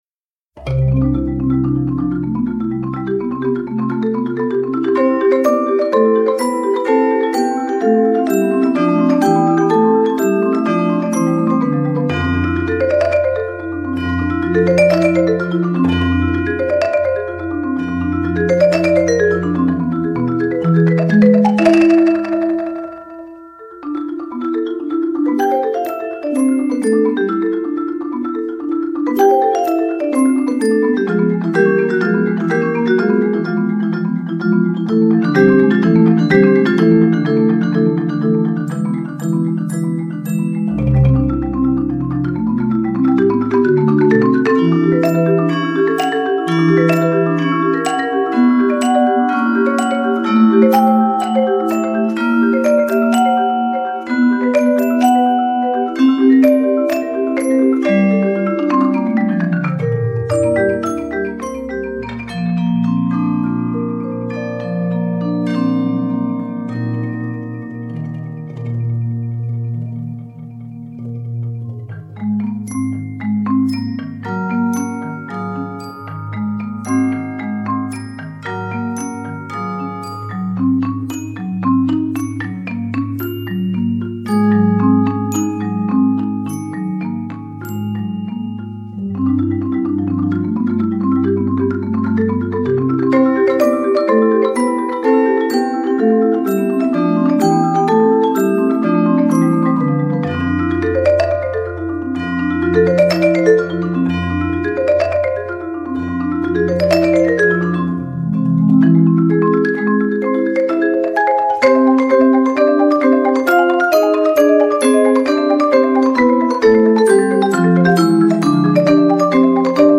Voicing: Percussion Septet